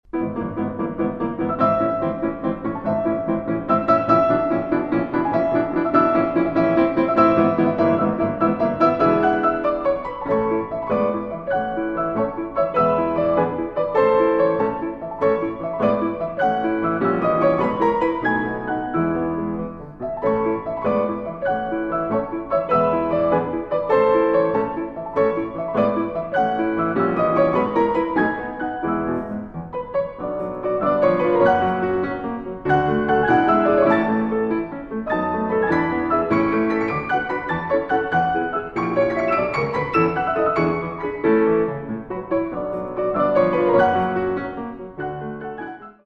Molto allegro (3:12)